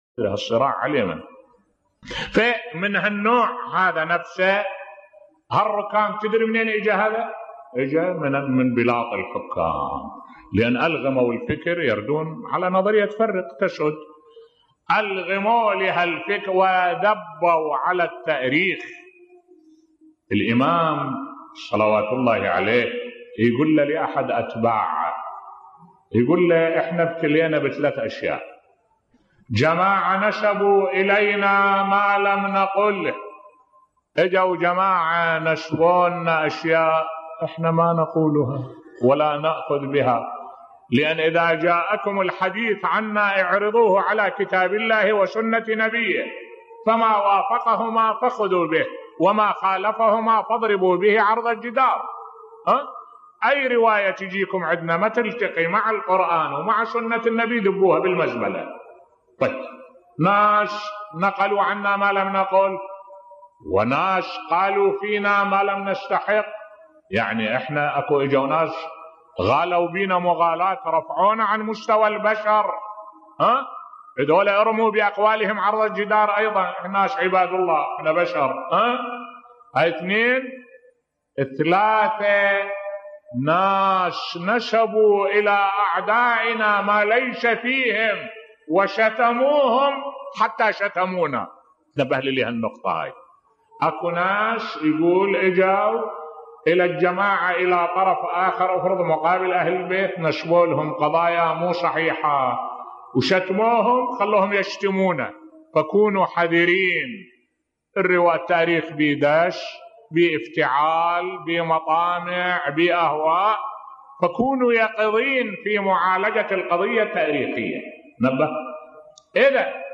ملف صوتی إحذر أن تكون من هؤلاء الثلاثة بصوت الشيخ الدكتور أحمد الوائلي